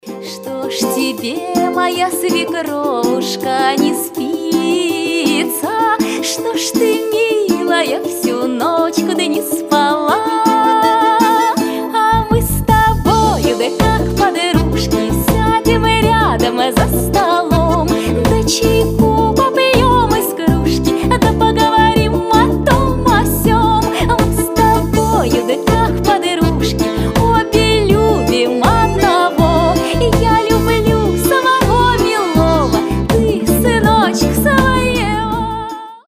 • Качество: 320, Stereo
Народные
добрые